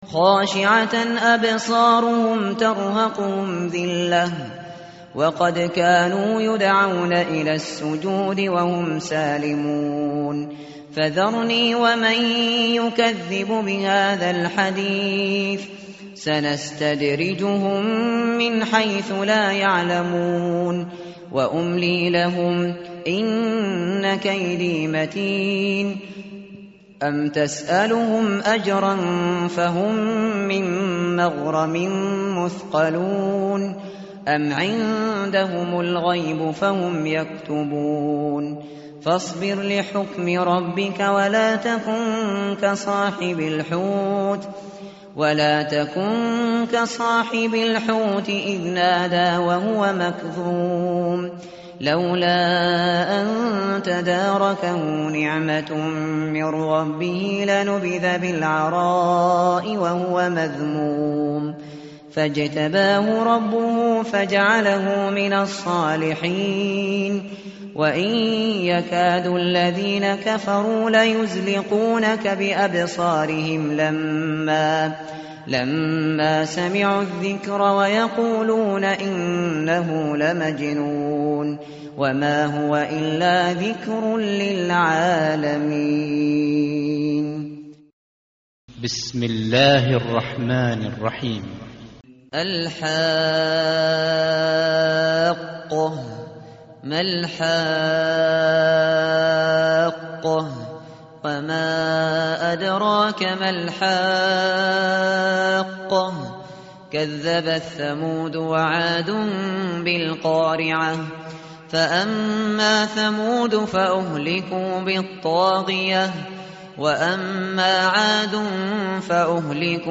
tartil_shateri_page_566.mp3